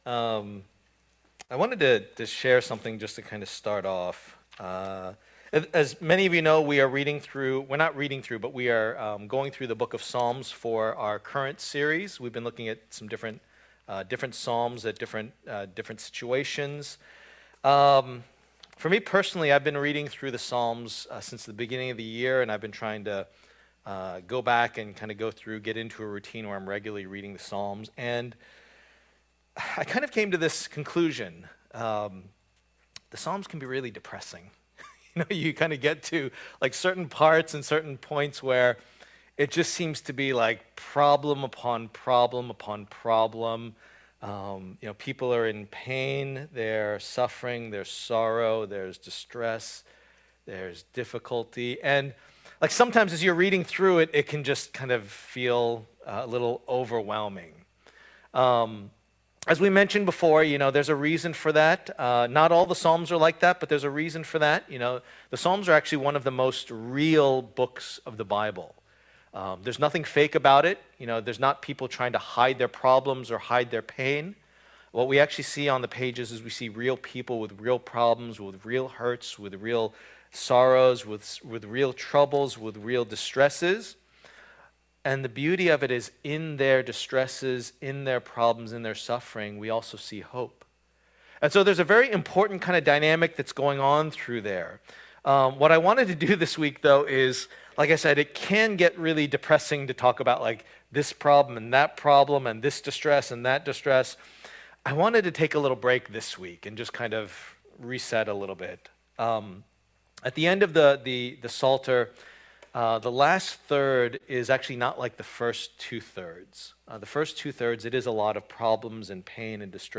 Passage: Psalm 95:1-11 Service Type: Lord's Day